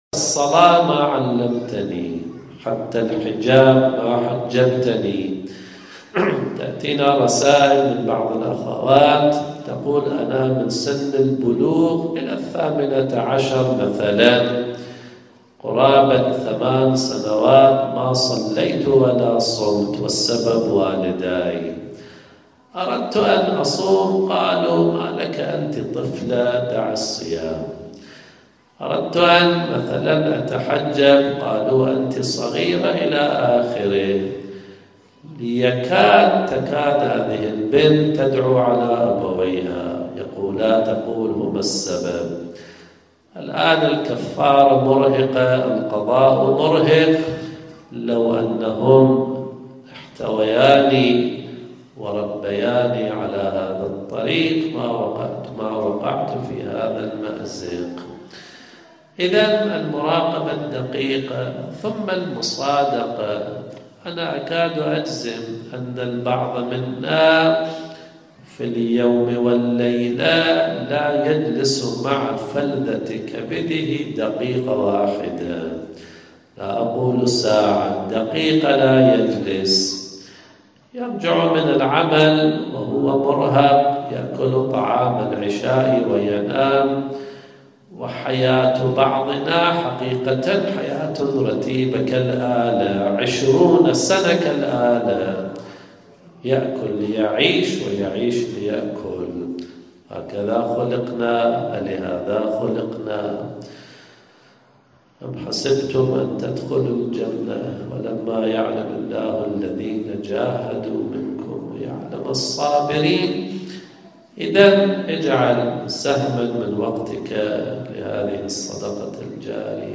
إفتتاح مسجد الامام علي في كوبنهاجن - شبكة رافـد للتنمية الثقافية